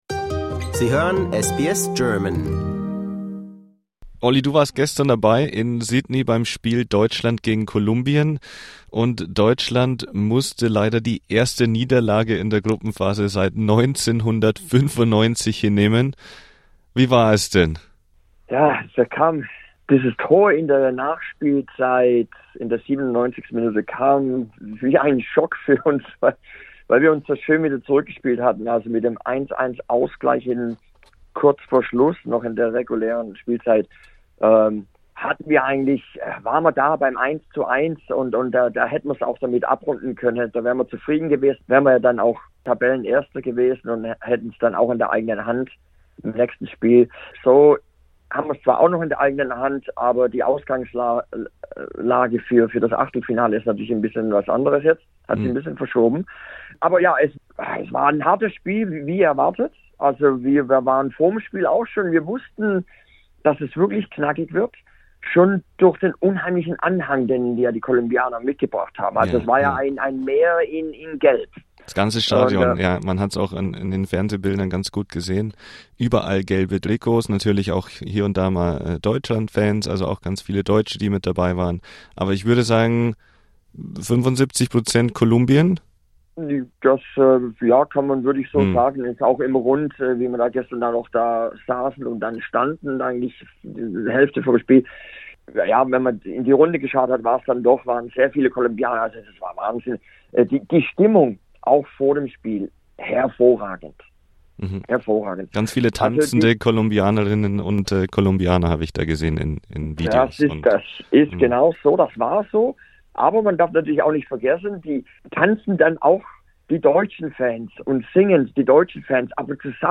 SBS hat mit ihm über die Geschehnisse auf und abseits des Platzes gesprochen.